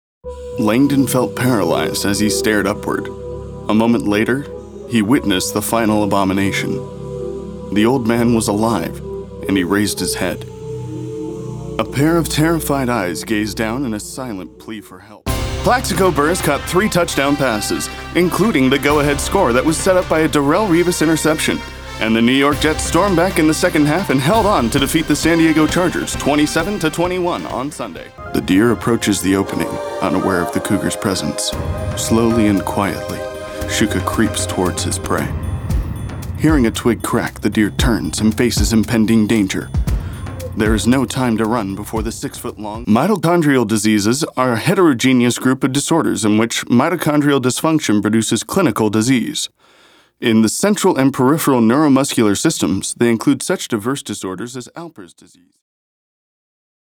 Actor for Voice Over
Demos